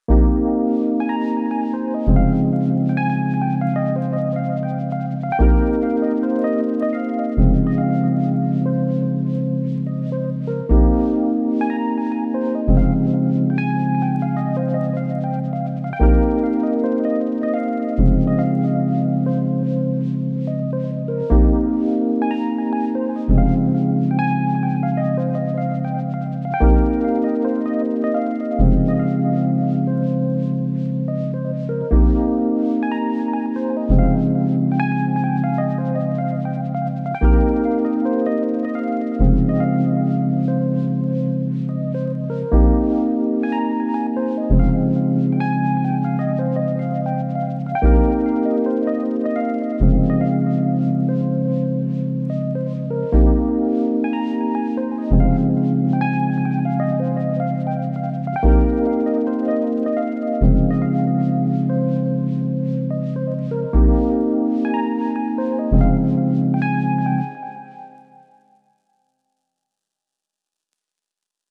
An ambient piece I created.